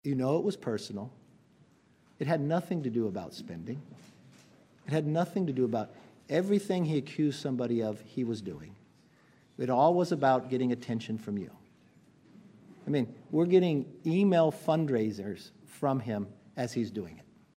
McCarthy spoke to reporters following the vote to remove him from the Speakership and said this about Matt Gaetz: